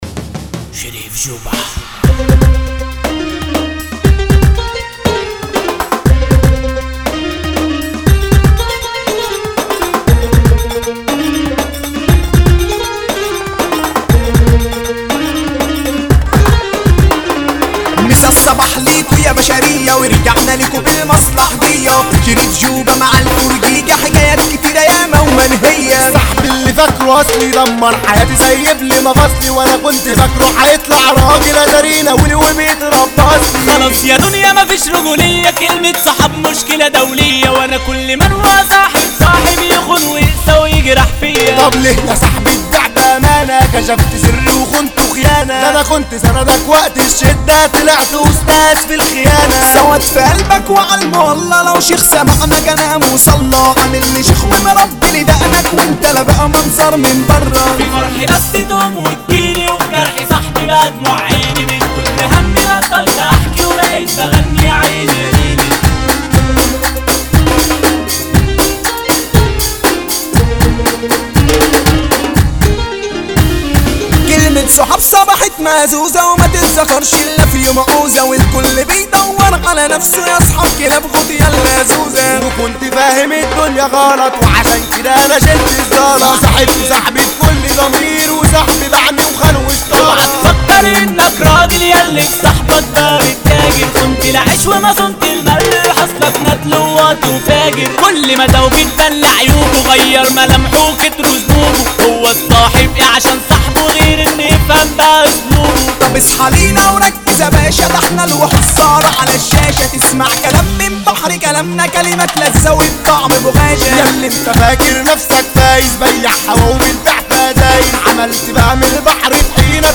مهرجان